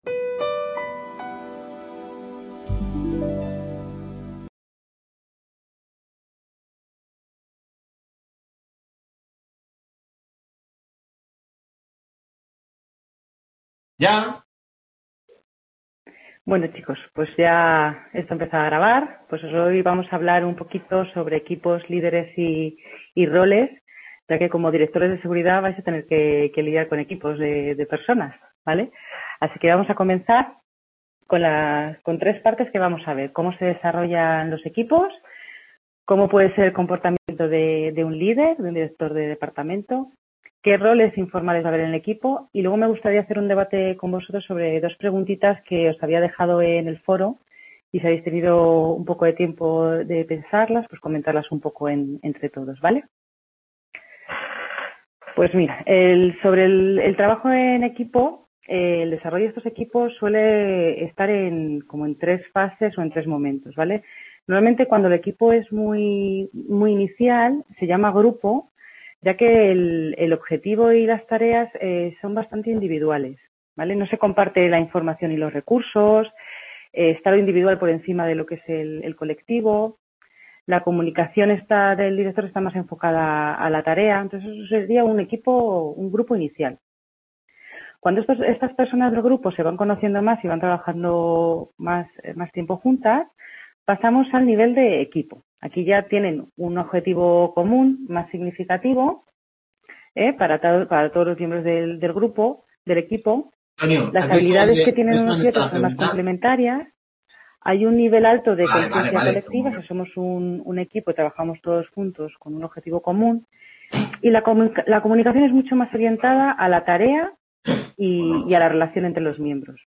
DS XVII Tutoria 1ª sobre gestión de recursos humanos | Repositorio Digital